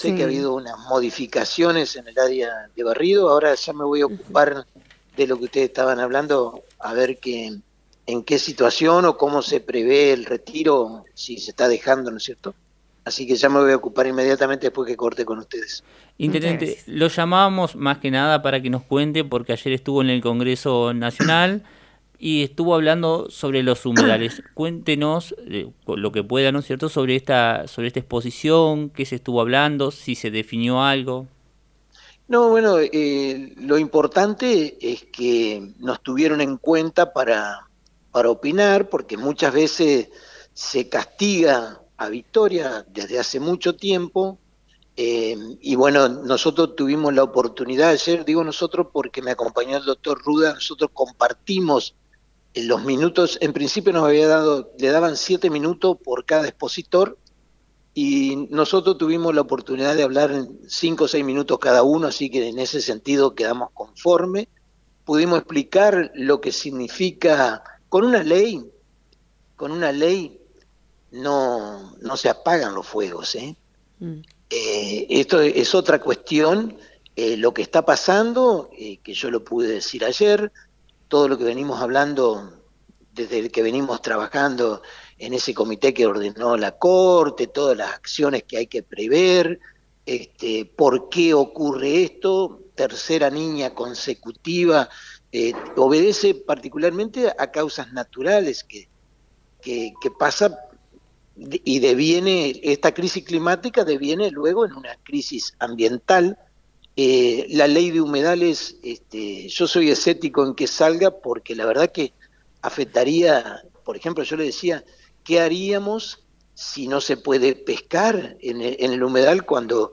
Así lo informó el Intendente Domingo Maiocco en diálogo con FM 90.3. También comentó sobre obras viales, terrenos usurpados y la propuesta presentada relacionada a los incendios en las islas del Delta.